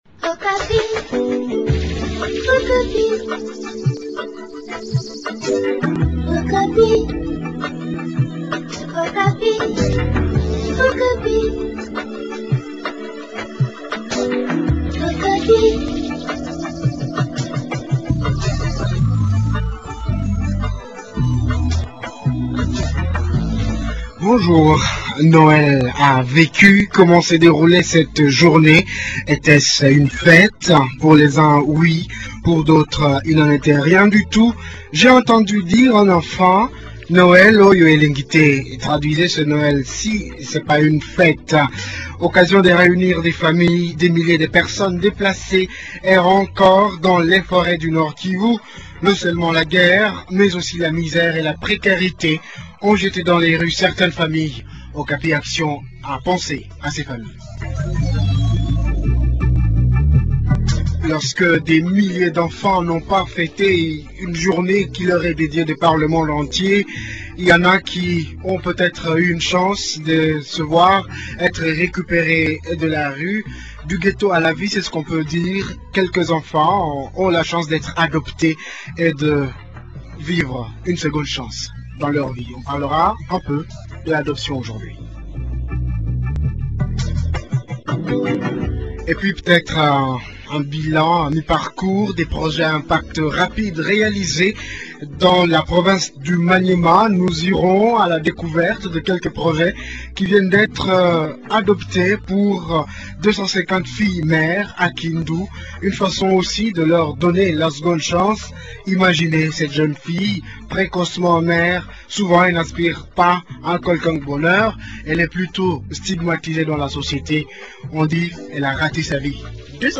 Reportage Okapi Action dans une famille démunie.